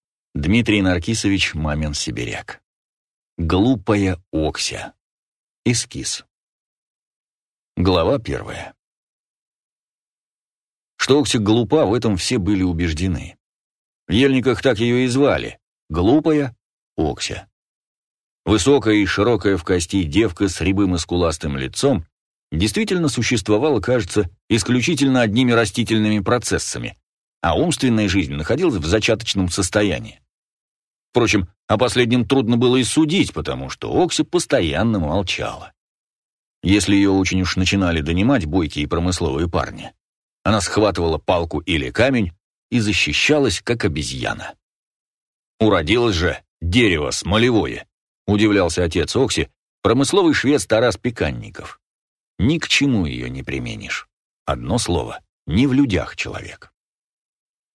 Аудиокнига Глупая Окся | Библиотека аудиокниг